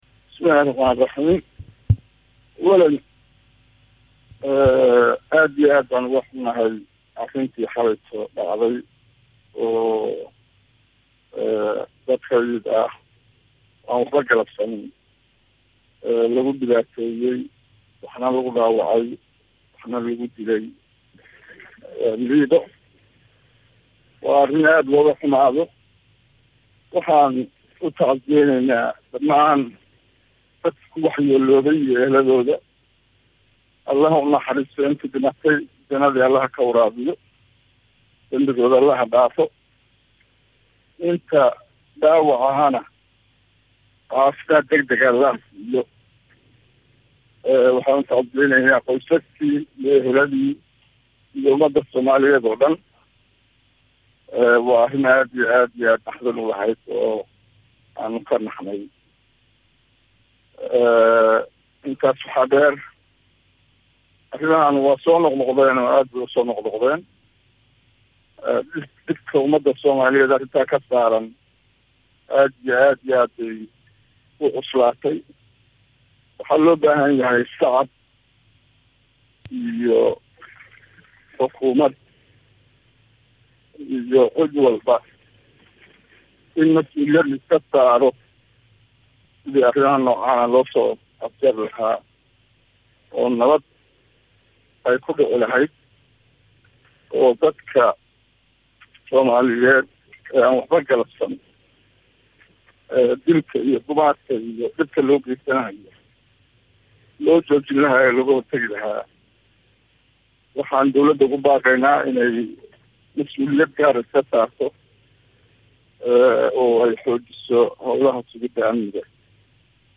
DHAGEYSO_-RW-Cabdiweli-Sheekh-oo-si-adag-uga-hadlay-weerarkii-Xeebta-LiidoWareysi-Xasaasi-ah-_-Saadaal-Media.mp3